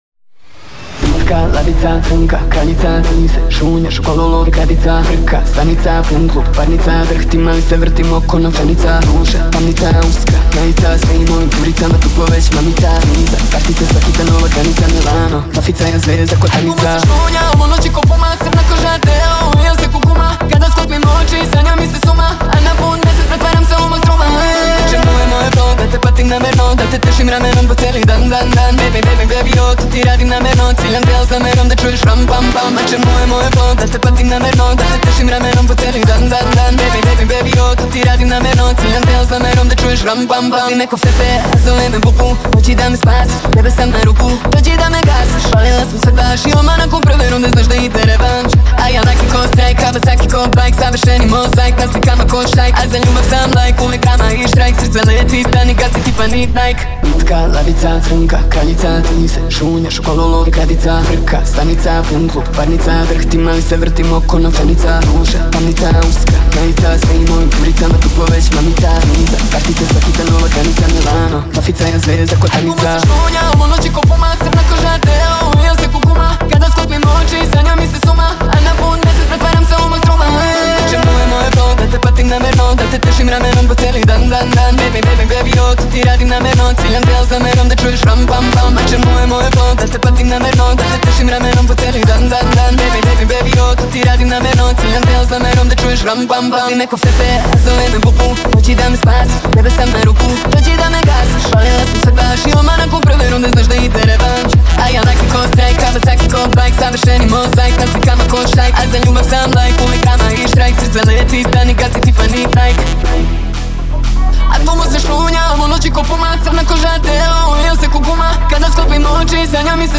REMIXES - MP3 FILES